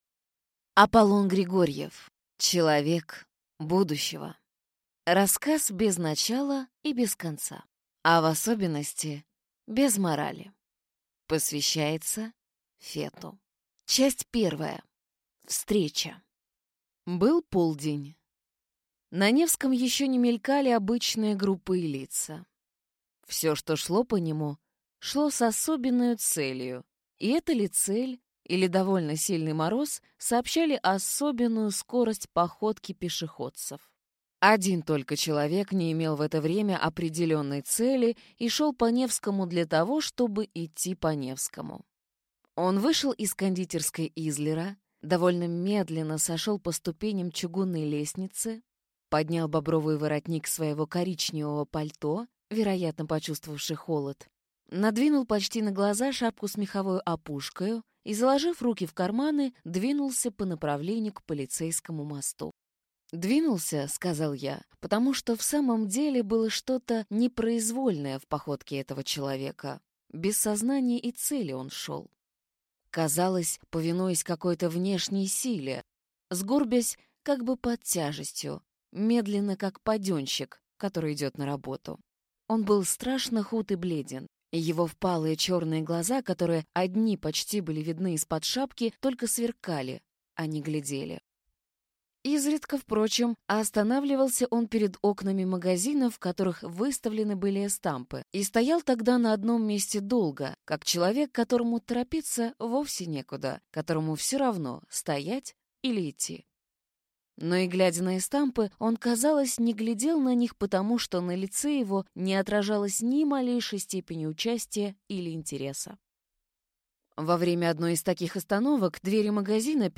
Аудиокнига Человек будущего | Библиотека аудиокниг